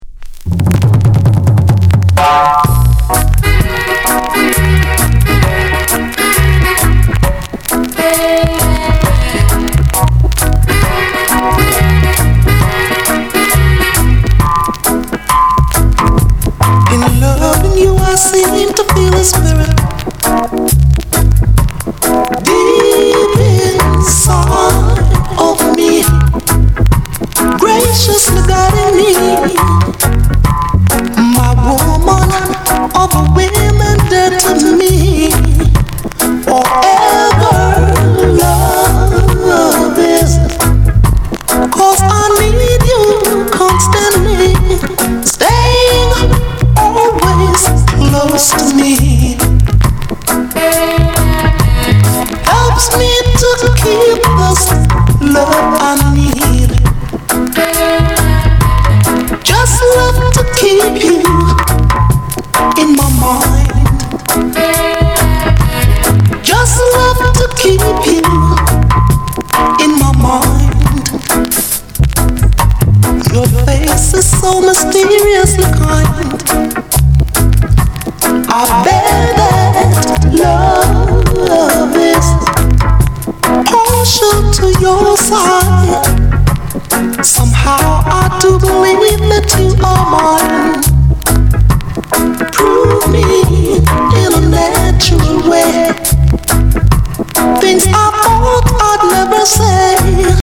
Genre: Reggae/ Lovers Rock